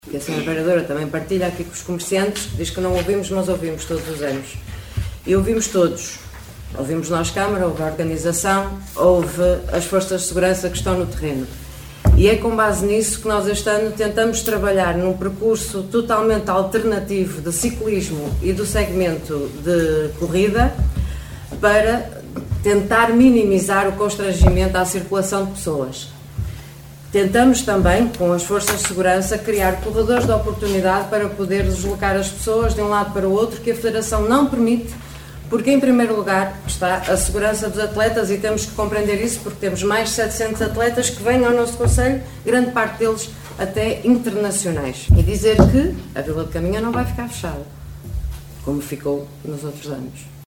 Excertos da última reunião de câmara, no passado dia 7 de Fevereiro, onde foram aprovados os apoios financeiros à Associação Triatlo de Caminha para a realização do Triatlo Longo, Meia Maratona e Corrida de São Silvestre, cujos percursos e condições se mantêm, segundo os protocolos, idênticos às edições dos anos anteriores.